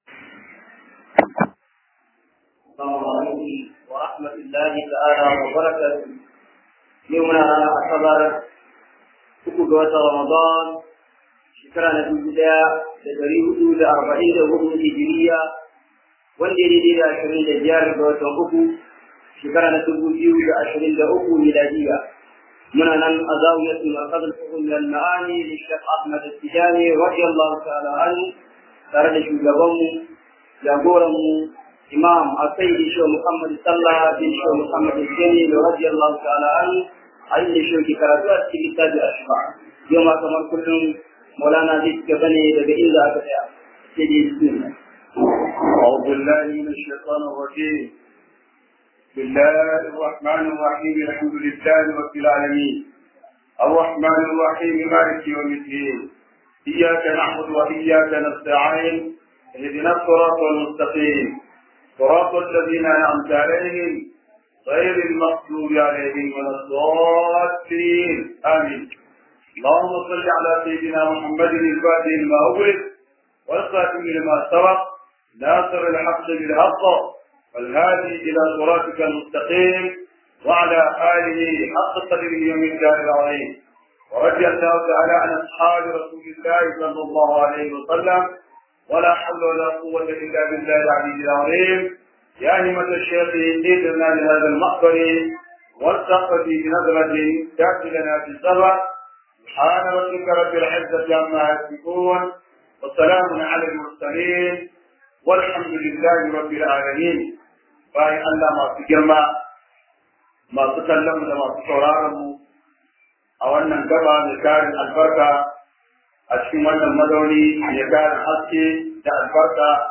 Fityanumedia Audios is a platform dedicated to sharing audio files of lectures from renowned Islamic scholars.